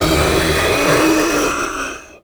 controller_die_2.ogg